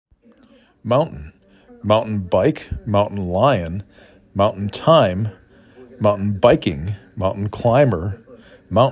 8 Letters, 2 Syllables
m ow n t ə n